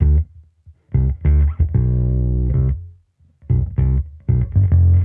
Index of /musicradar/sampled-funk-soul-samples/95bpm/Bass
SSF_PBassProc2_95D.wav